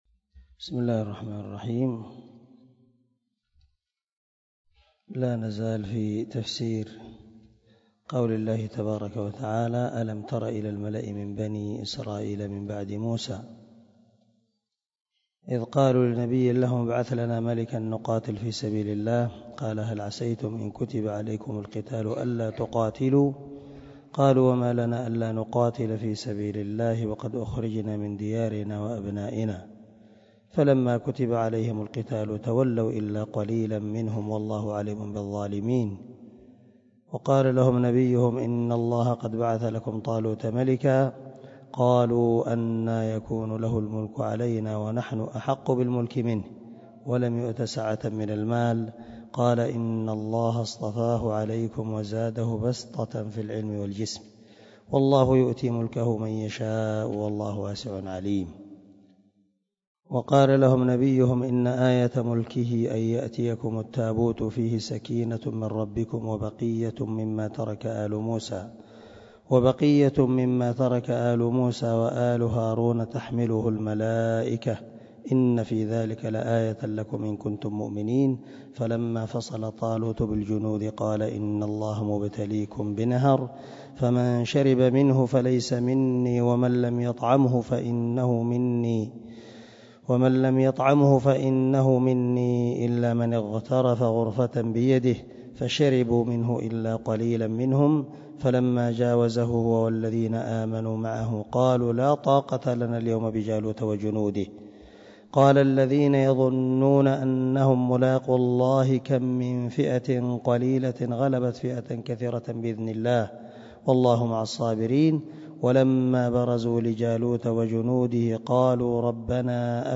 130الدرس 120 تابع تفسير آية ( 246 – 252 ) من سورة البقرة من تفسير القران الكريم مع قراءة لتفسير السعدي
دار الحديث- المَحاوِلة- الصبيحة.